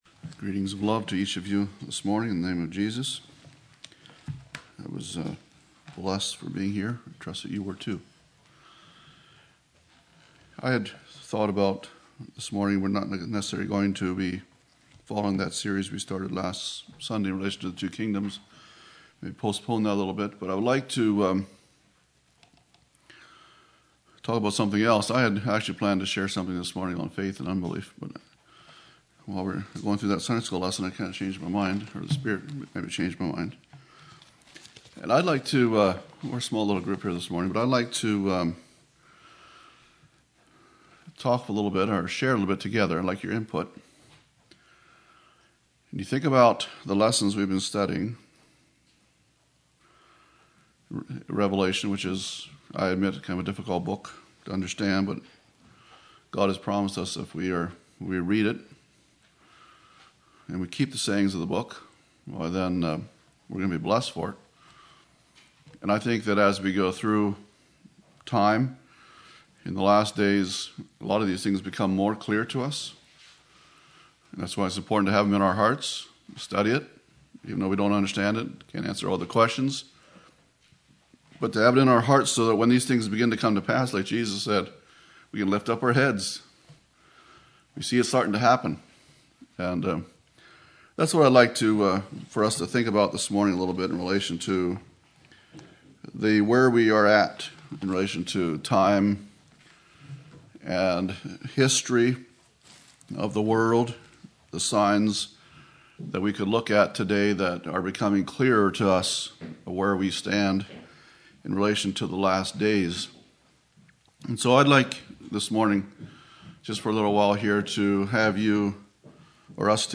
Signs of the Last Days (open discussion)